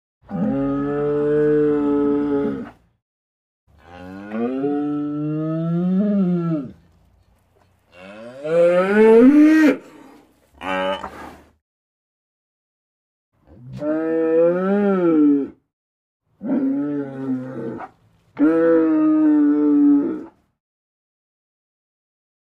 Bull Bellows; Various, Close Perspective